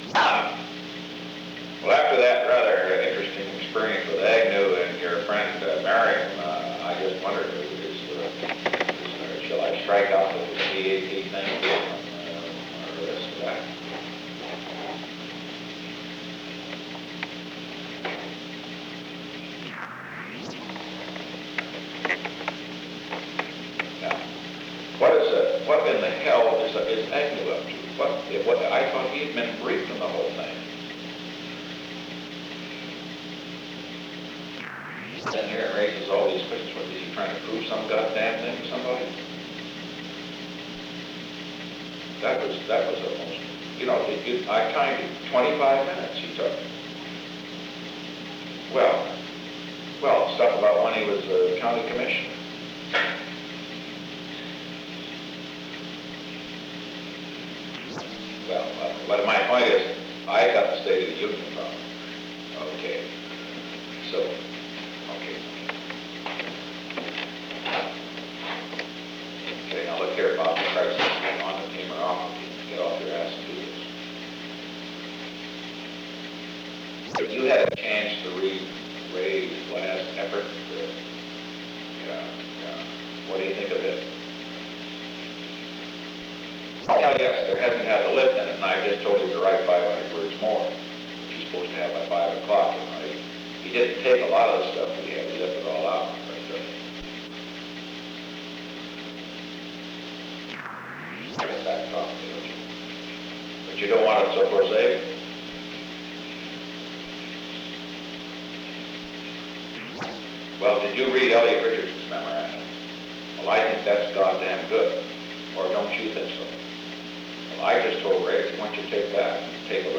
On January 13, 1972, President Richard M. Nixon and John D. Ehrlichman met in the President's office in the Old Executive Office Building at an unknown time between 3:41 pm and 3:50 pm. The Old Executive Office Building taping system captured this recording, which is known as Conversation 314-002 of the White House Tapes. Nixon Library Finding Aid: Conversation No. 314-2 Date: January 13, 1972 Time: 3:41 pm - unknown time before 3:50 pm Location: Executive Office Building The President talked with John D. Ehrlichman.